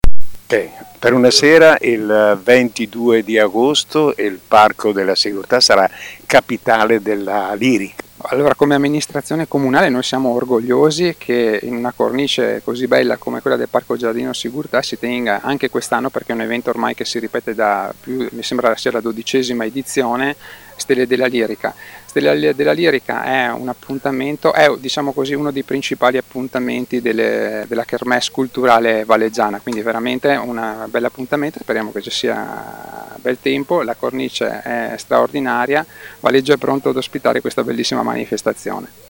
Le interviste del nostro corrispondente
Sindaco di Valeggio, Alessandro Gardoni